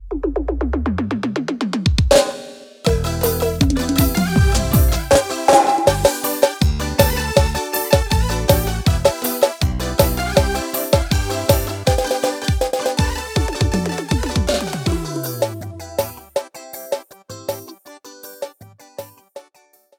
• Demonstrativo Piseiro: